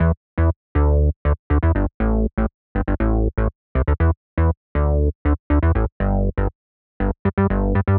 23 Bass PT2.wav